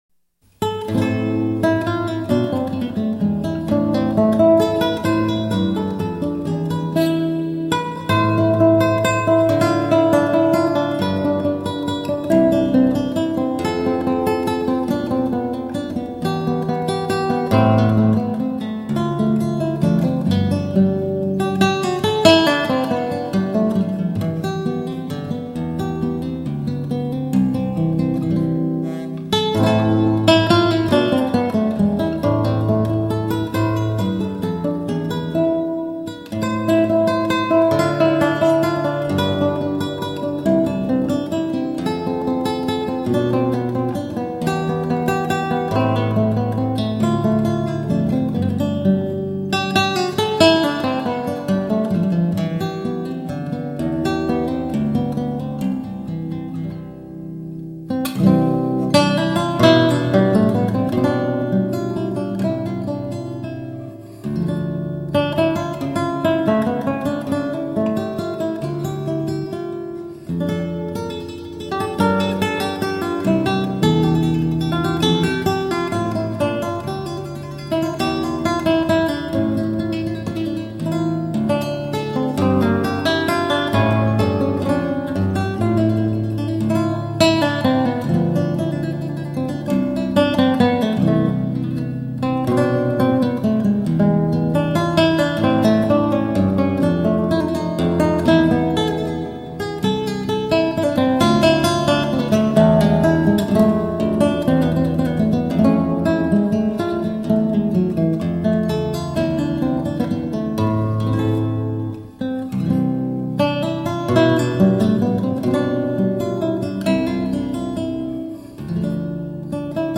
Classical, Baroque, Instrumental, Lute